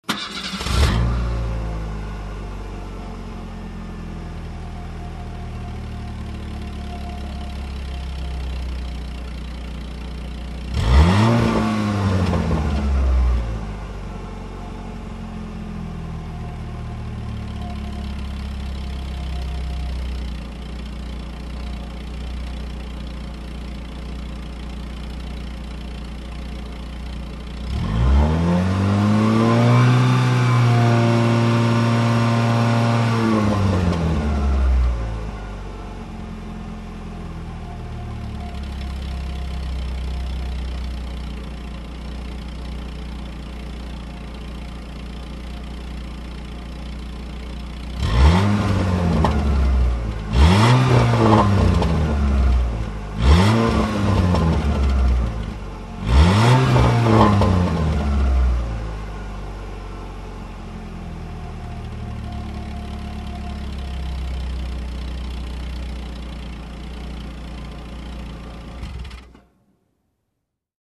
Звук завода машины, газование и глушение